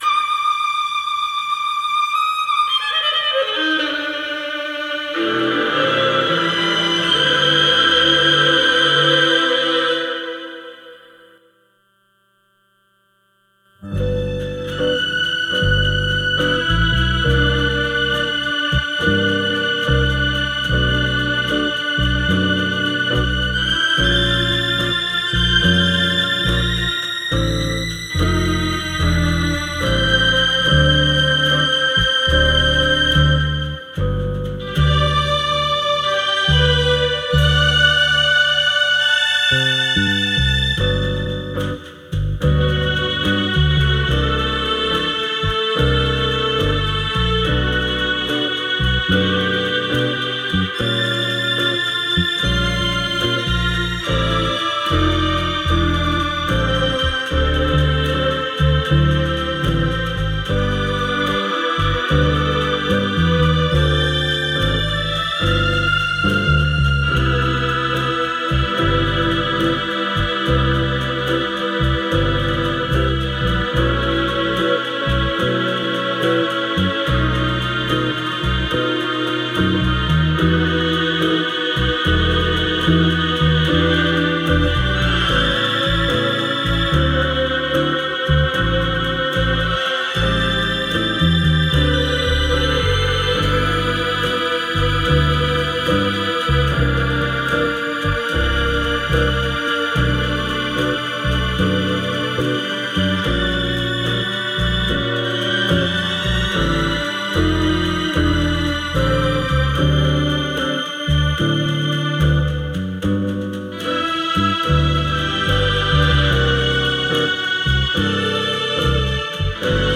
This is an instrumental backing track cover.
• Key – A♭
• Without Backing Vocals
• No Fade